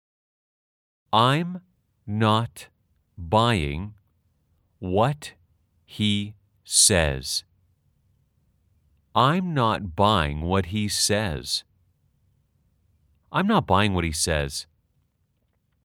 / 아임 나앗 바잉 웟히 / 세에즈 /
아주 천천히-천천히-빠르게 3회 반복 연습하세요.